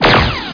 blaster.mp3